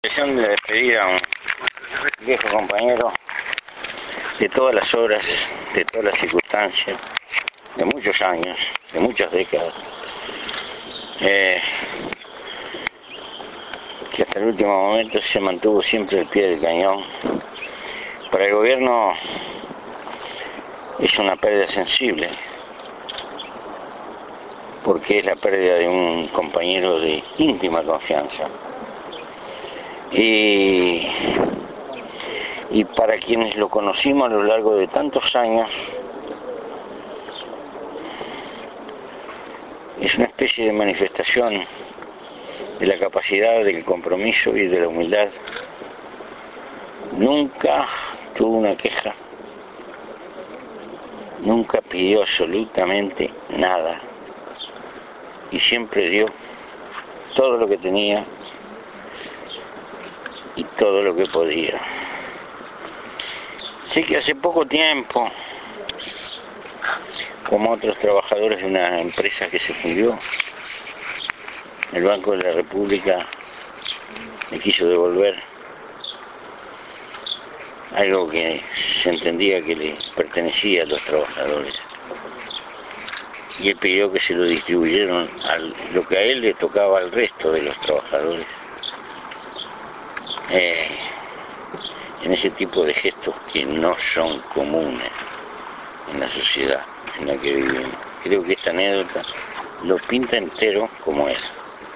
En el Cementerio Central, con honores f�nebres de Estado, en presencia del Presidente de la Rep�blica y autoridades nacionales se llev� a cabo el sepelio del subsecretario de Defensa Nacional.
En nombre del Poder Ejecutivo hizo luego uso de la palabra el ministro Rosadilla, quien subray� el compromiso de Castell� con la sociedad.